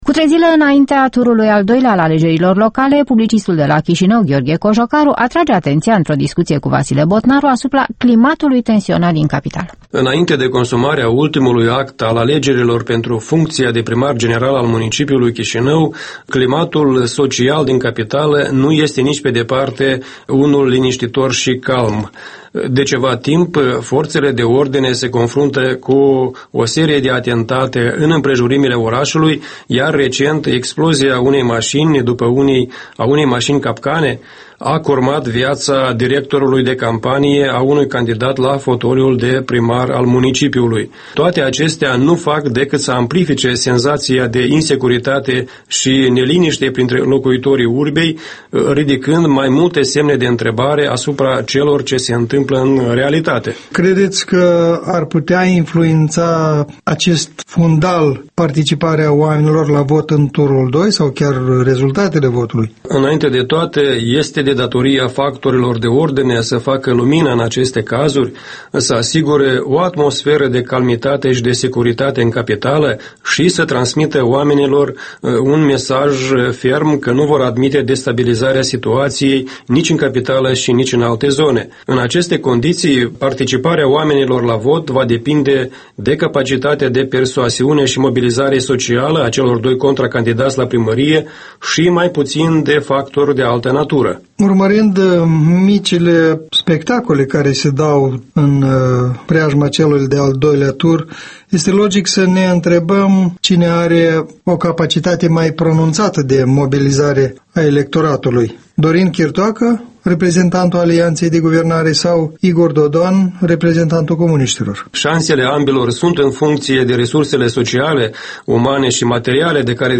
In dialog despre apropiatul tur doi al alegerilor